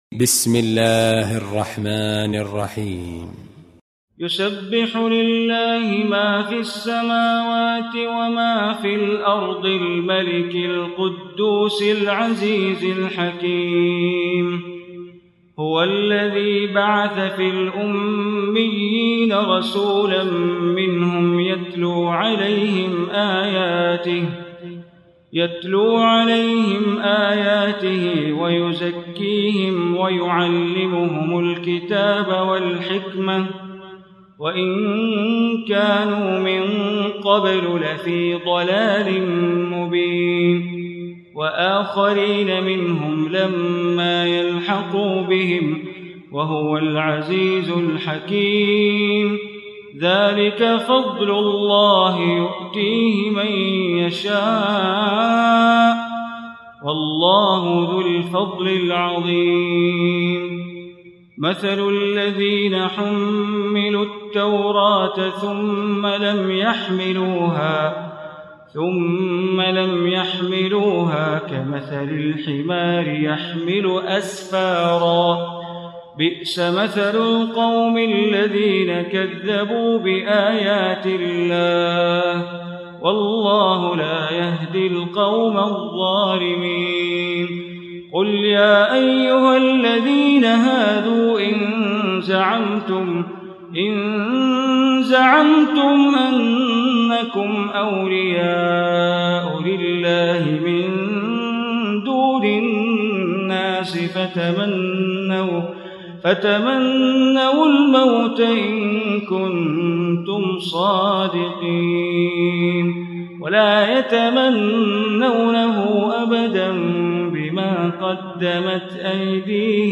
Surah Juma Recitation by Sheikh Bandar Baleela
Surah Juma, listen online mp3 tilawat / recitation in Arabic in the beautiful voice of Imam e Kaaba Sheikh Bandar Baleela. Surah Juma is 62 chapter of Holy Quran.